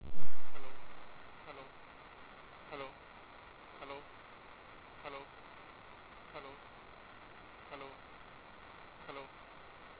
我还附加了我们在8kHz 和16KHz 启用 AGC 时记录的示例记录文件。 在16KHz 时、与在扬声器中具有相同音量的8kHz 相比、录制的音频非常清晰、但会出现一些额外的噪声、您可以注意到。